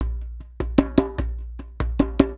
Index of /90_sSampleCDs/USB Soundscan vol.36 - Percussion Loops [AKAI] 1CD/Partition A/19-100JEMBE
100 JEMBE7.wav